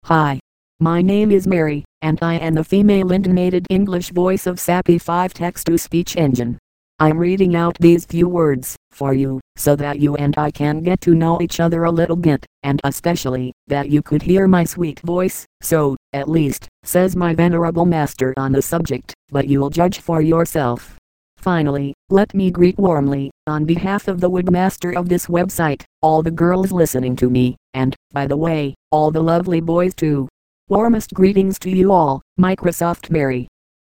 Texte de démonstration lu par Microsoft Mary (Voix féminine anglaise Sapi 5.1)
Écouter la démonstration de Microsoft Mary (Voix féminine anglaise Sapi 5.1)